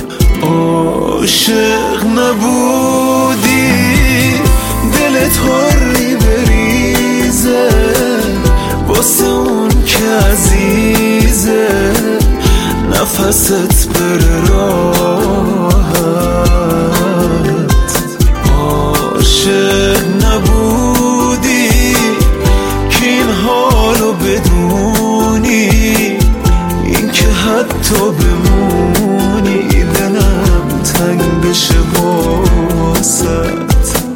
دانلود زنگ موبایل ملایم برای صدای زنگ گوشی